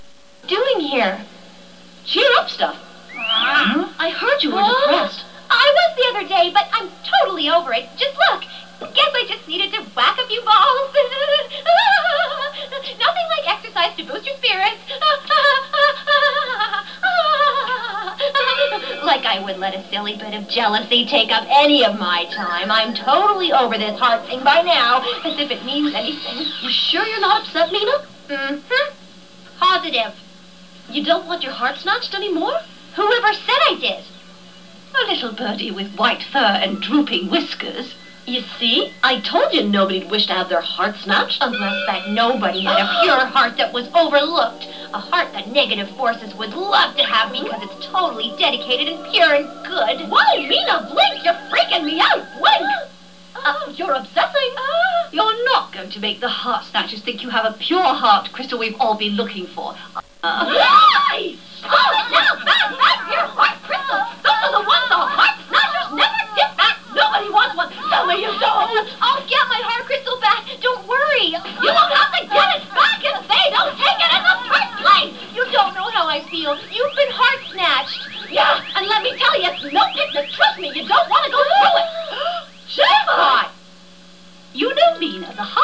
Serena hits some pretty high notes with that voice of hers >_<